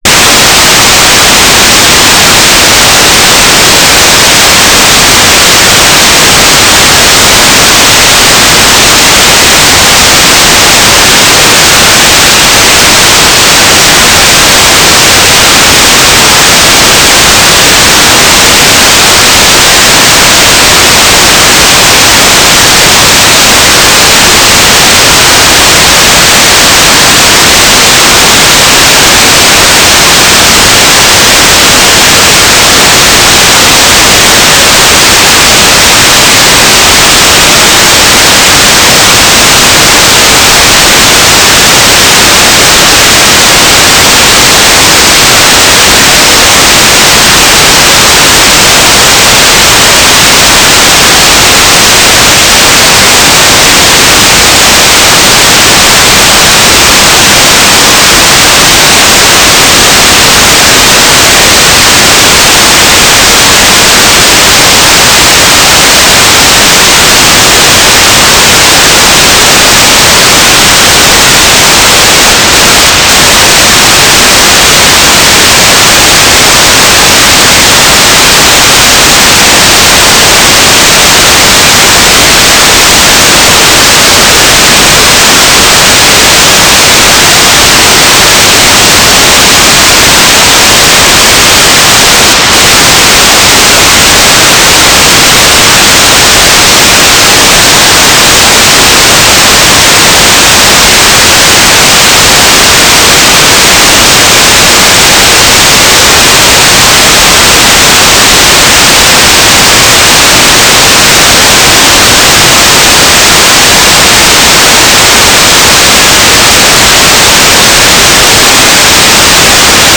"transmitter_description": "Mode U 9k6 FSK AX.25",